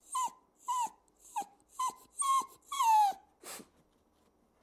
Wolf howl
标签： howl wolves wolf howling dog werewolf
声道立体声